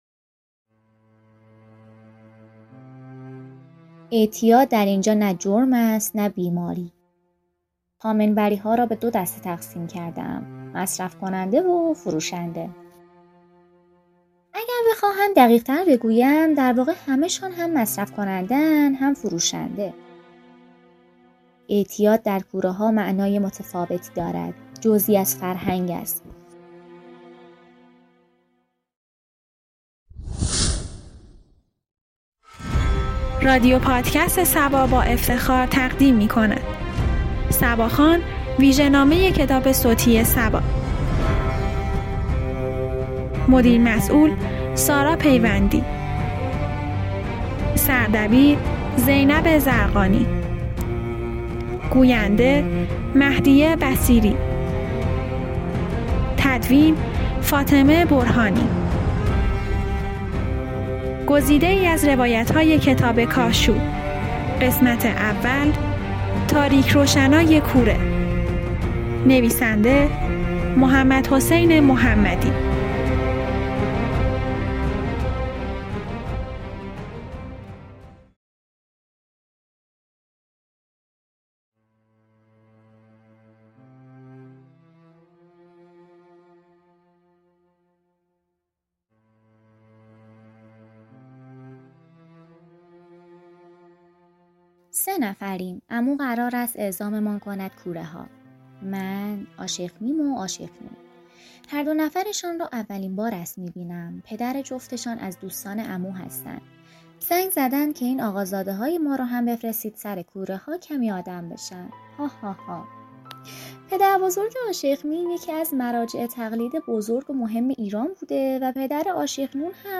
صبا (ویژه‌نامه کتاب صوتی کاشوب-قسمت اول)